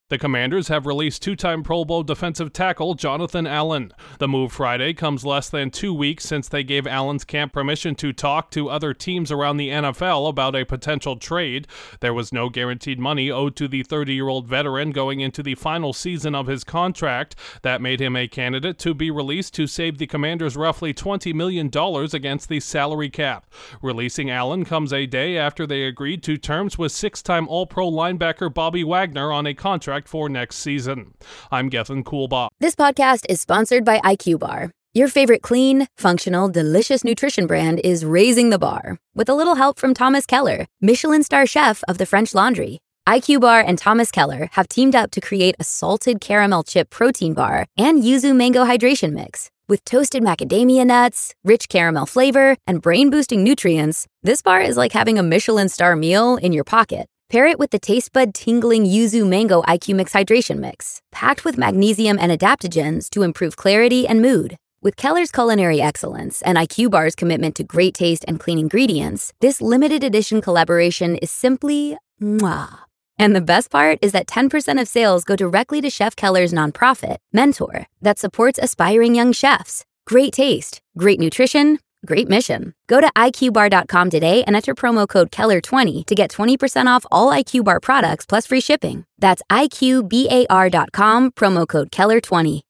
An NFC West squad is moving on from one of its defensive stars ahead of the new NFL league year. Correspondent